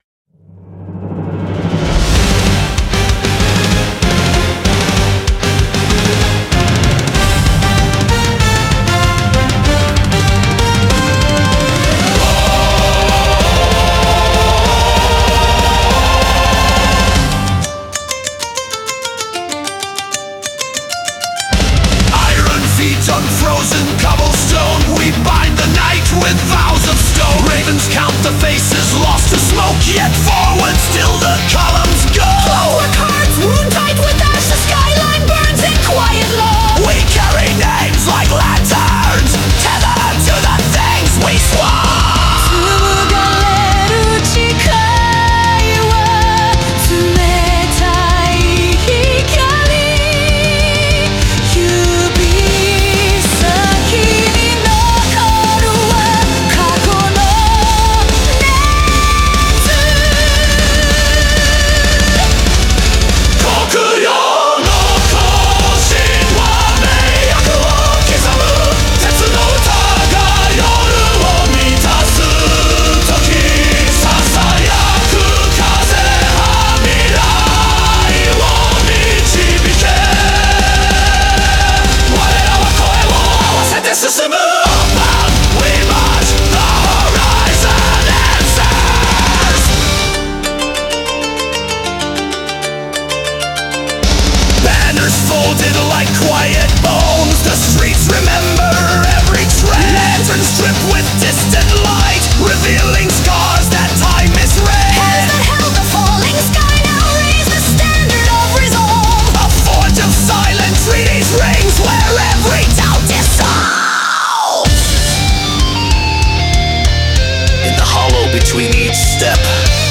Melodic Power Metal
At 220 BPM